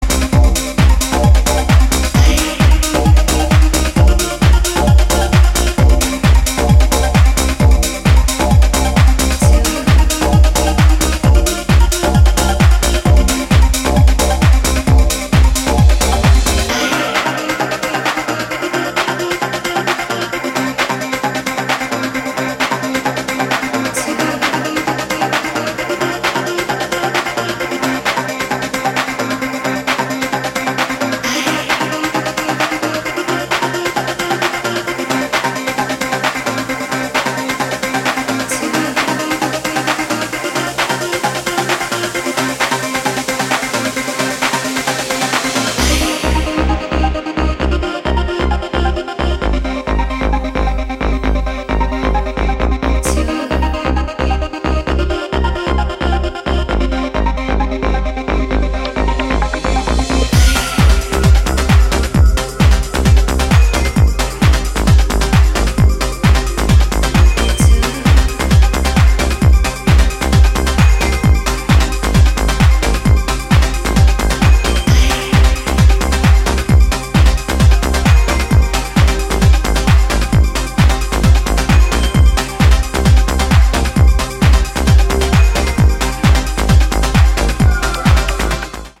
ピークタイム路線の強力な内容ですね。